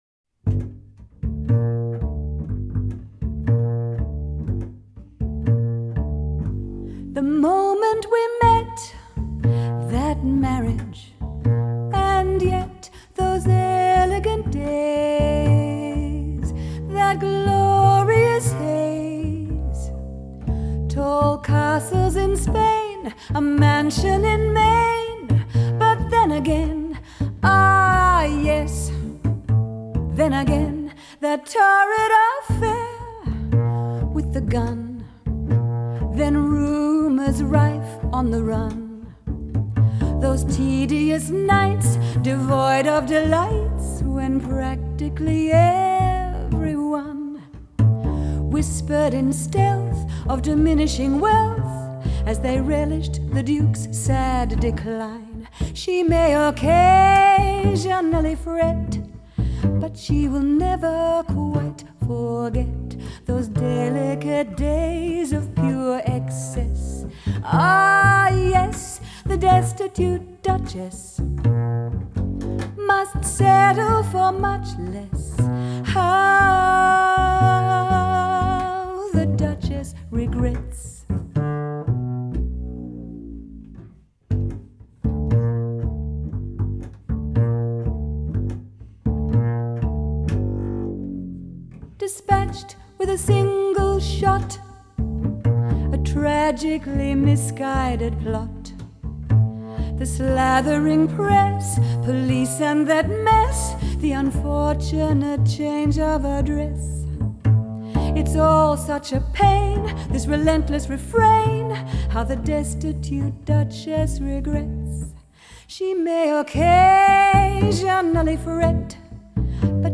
An uplifting auditory experience.